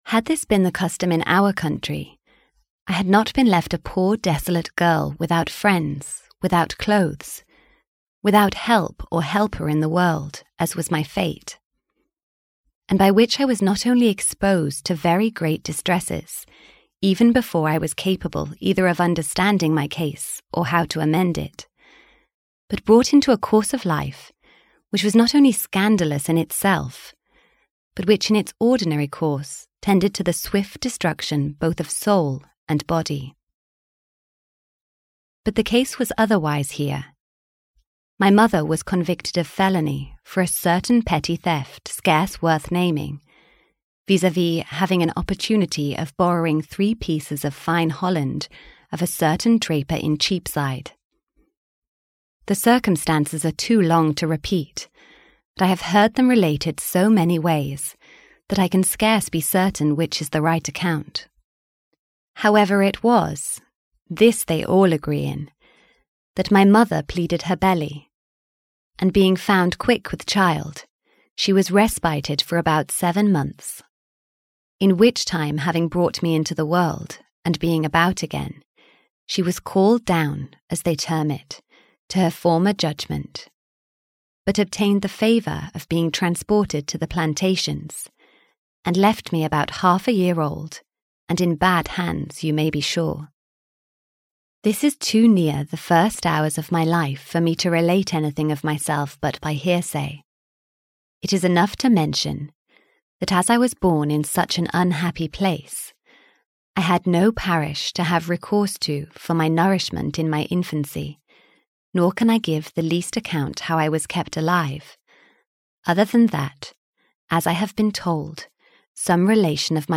LUST Classics: Moll Flanders (EN) audiokniha
Ukázka z knihy